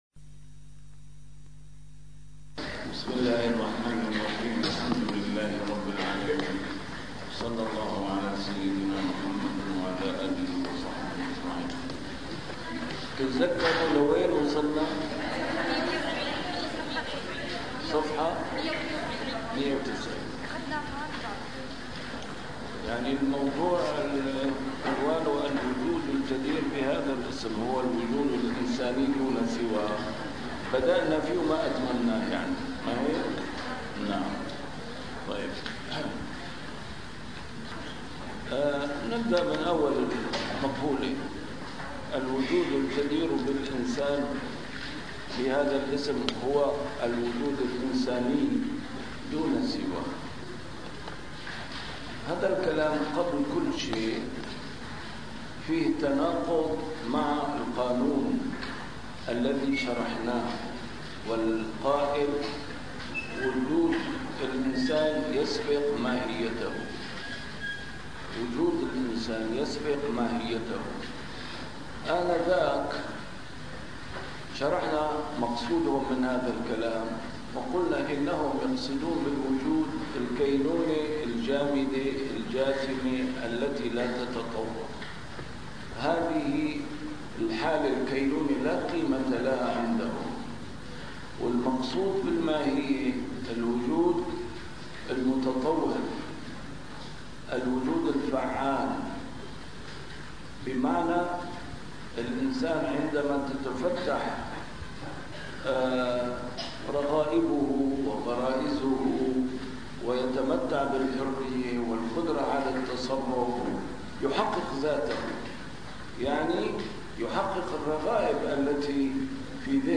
المحاضرة السادسة: تتمة نقد المذهب الوجودي 2